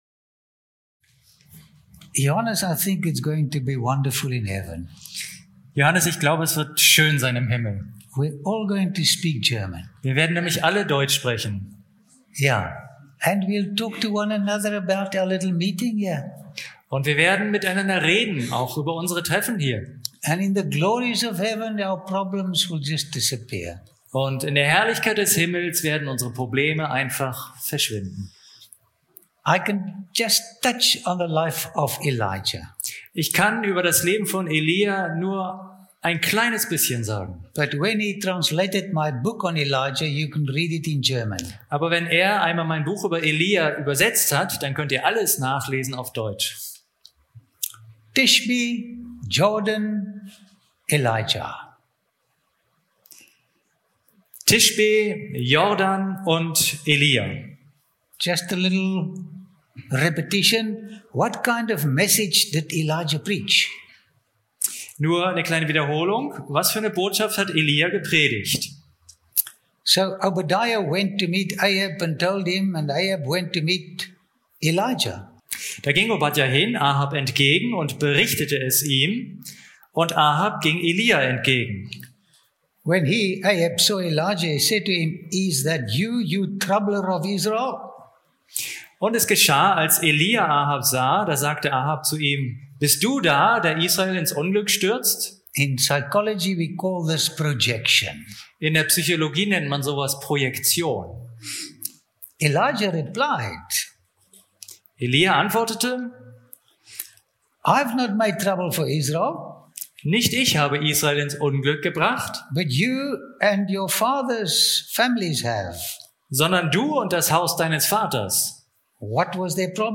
In den reich bebilderten Vorträgen erhält der Zuschauer nicht nur faszinierende Einblicke in vergangene Kulturen, sondern begegnet Menschen, die vor Tausenden von Jahren ganz ähnliche Freuden und Sorgen hatten wie wir – und deren ermutigende Erfahrungen auch heute noch erlebbar sind, wenn wir von ihnen lernen und den verlorenen Schatz des Vertrauens in Gott wiederfinden.